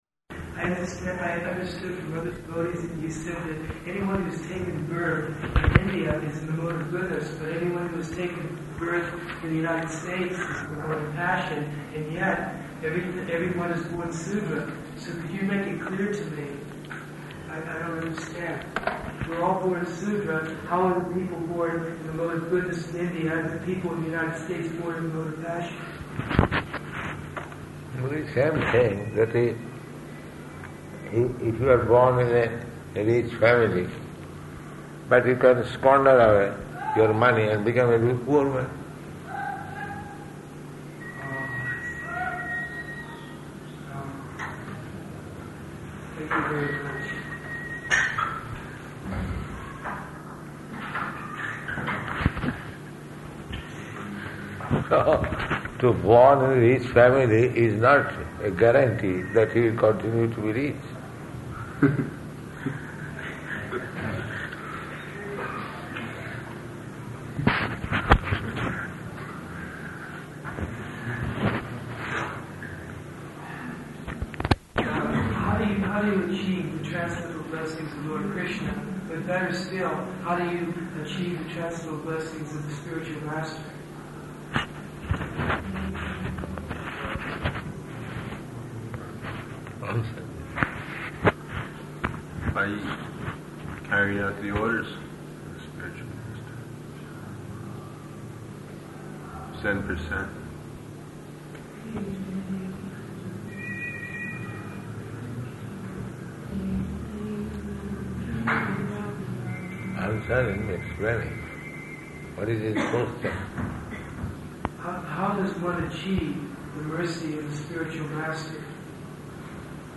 Conversation at Bhaktivedanta Manor
Type: Conversation